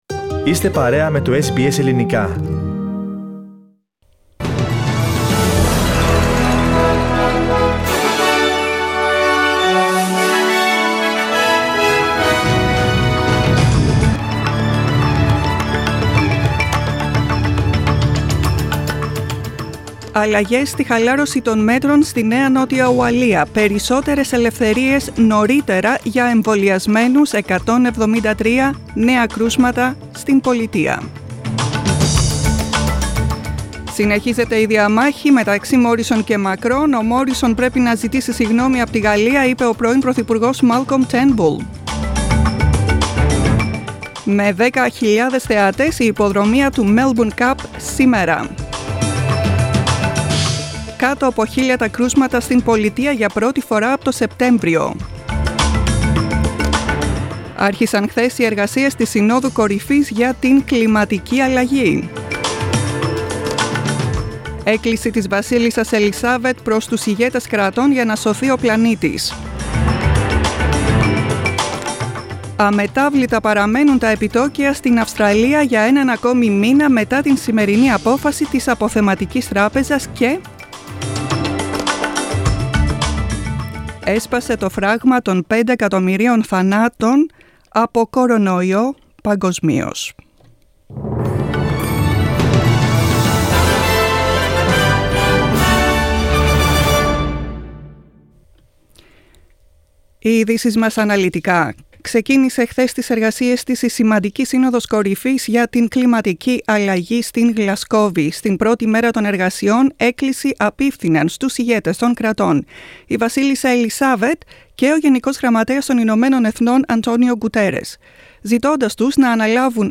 The main bulletin of the day from SBS Greek.